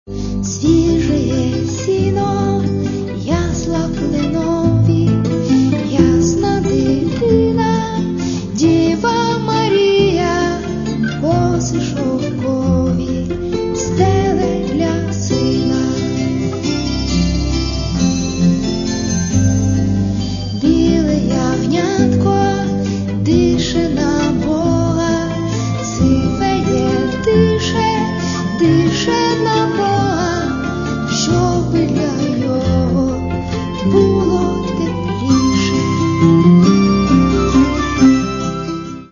Каталог -> MP3-CD -> Альтернатива